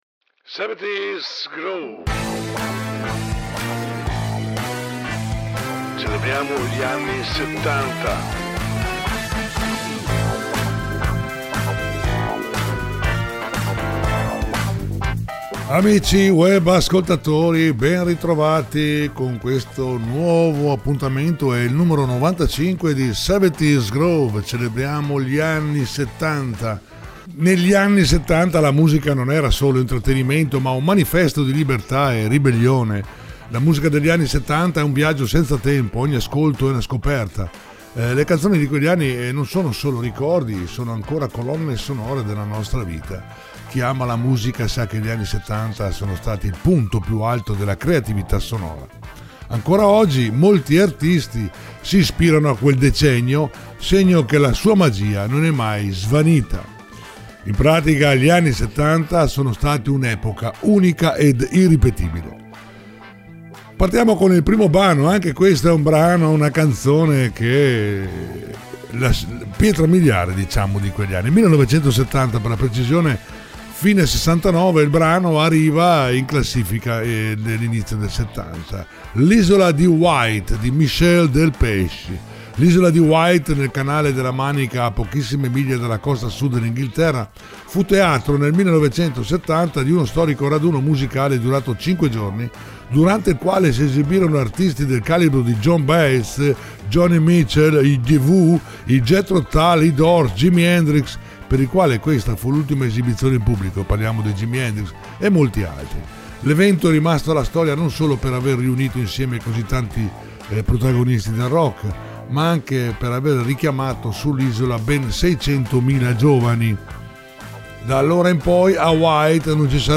70's Groove